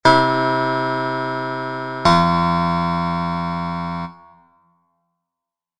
Demonstration of 13edo "V - I" cadential progression, audio